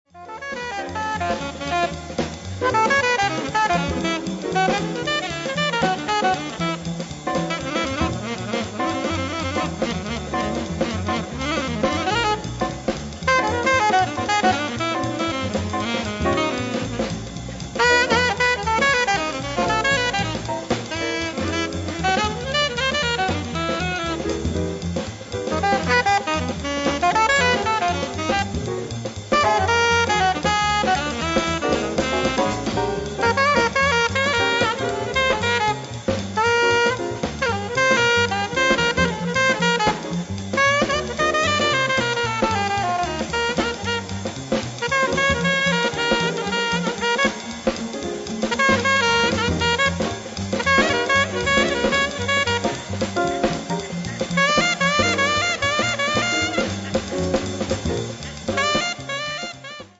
it has that swinging swagger to it.